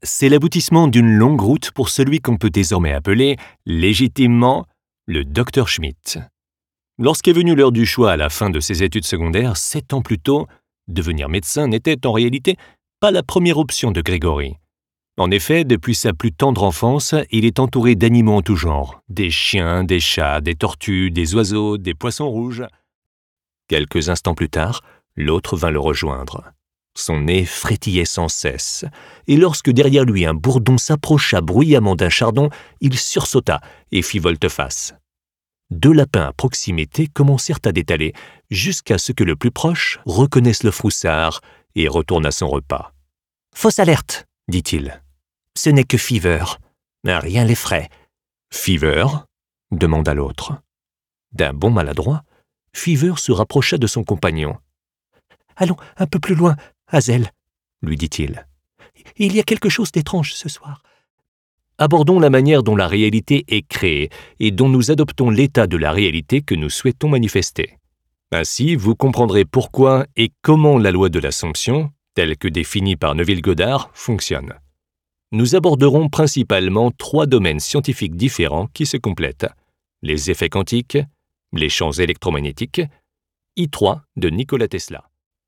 Male
My voice is warm, friendly and approachable, making it great for any story telling, with a natural and personal touch.
Audiobook Demo
Words that describe my voice are friendly, warm, natural.
All our voice actors have professional broadcast quality recording studios.
1106audiobook_demo.mp3